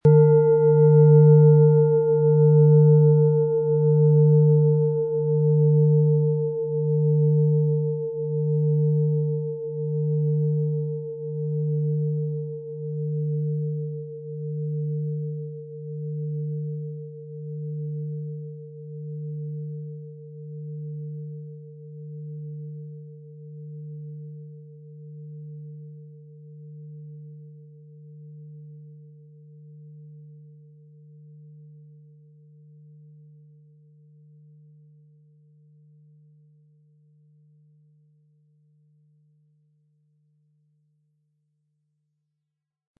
Planetenton 1
Thetawelle
Planetenschale® antik Gelassen und beruhigt sein & umhüllt und geschützt fühlen, mit Thetawellen, Ø 27,1 cm inkl. Klöppel